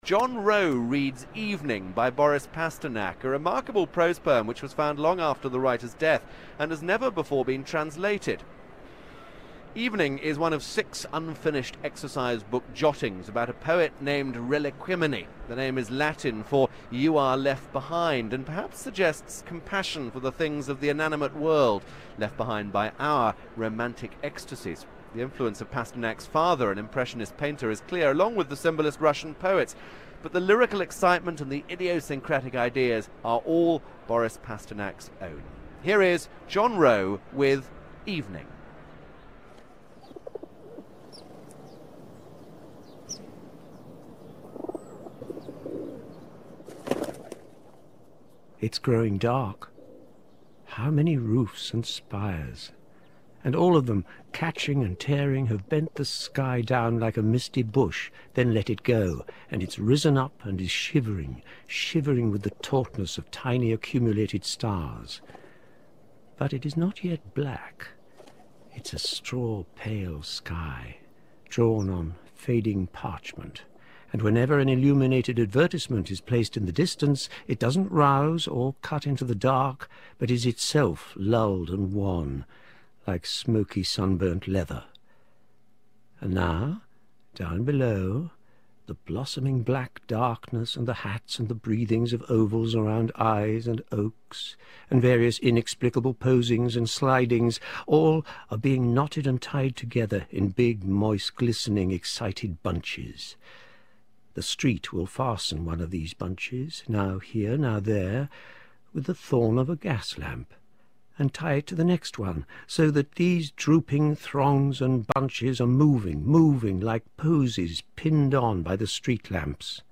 This piece of poetic prose is taken from ‘Early Prose’ in the book The Marsh of Gold, there entitled ‘Reliquimini’; a reading
for a Radio 3 broadcast